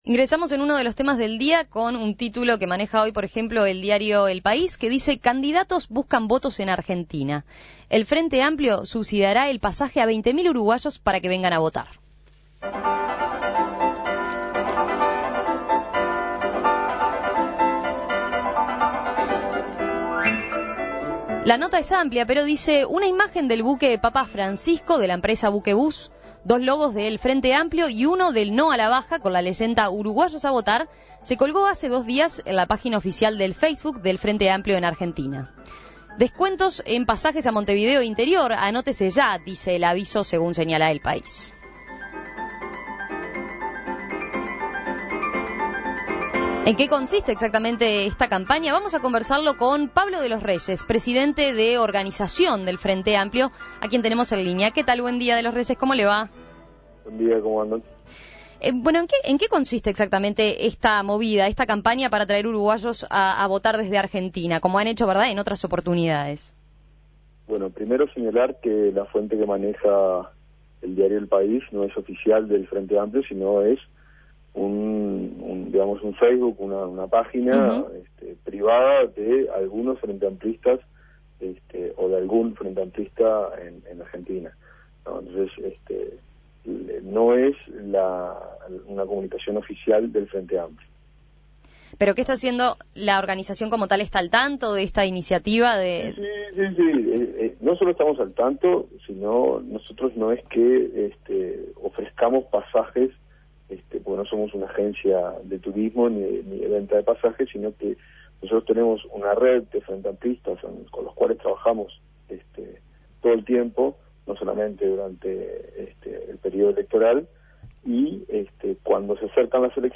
En diálogo con En Perspectiva